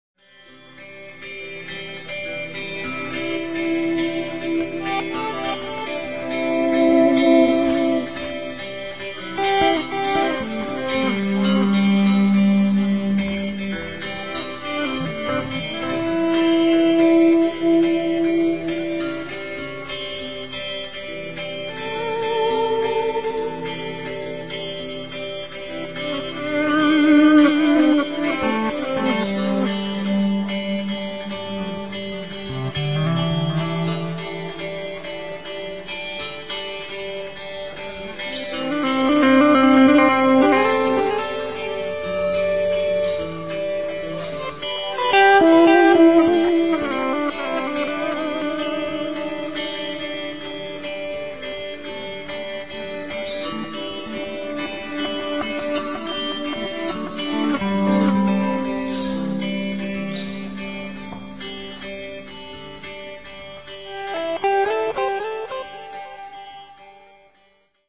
They are all first takes.